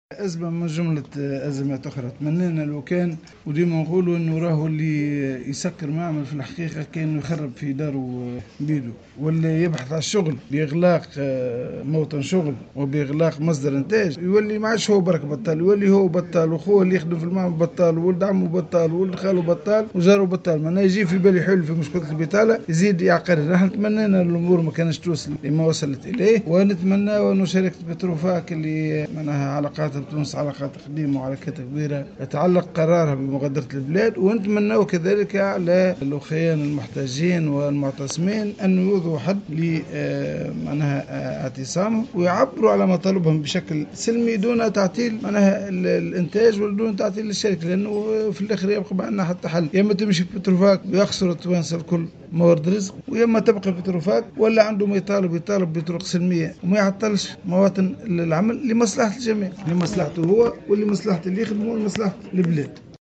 Le président du bloc parlementaire d’Ennahdha, Noureddine Bhiri, a appelé, dans une déclaration accordée à Jawhara Fm, aujourd’hui, jeudi 22 septembre 2016, à mettre fin aux sit-in qui ont bloqué la production à Petrofac, ce qui l’a poussé à arrêter ses activités en Tunisie.